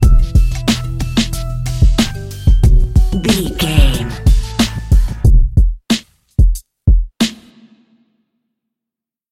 Aeolian/Minor
drum machine
synthesiser
electric piano
90s
hip hop
soul
Funk
acid jazz
energetic
bouncy
funky